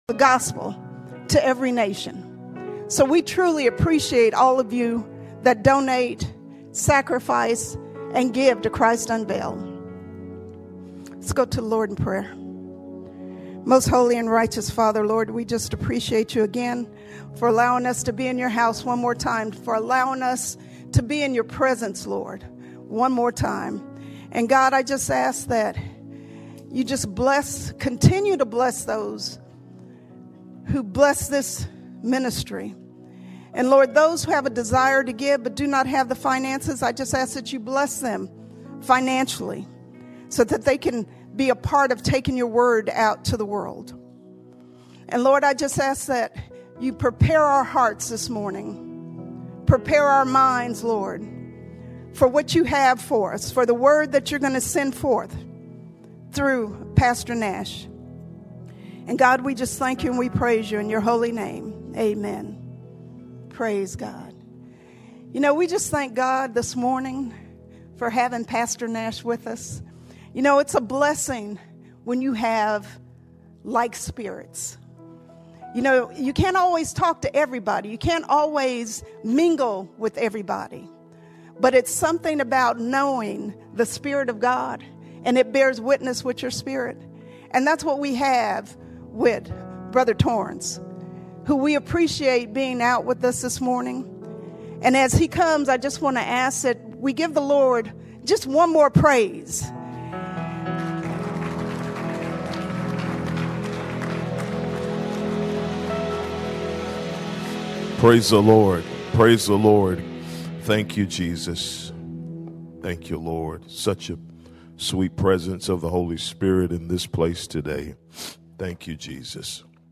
23 February 2026 Series: Guest Speakers All Sermons Holiness On The Inside Holiness On The Inside Holiness begins within.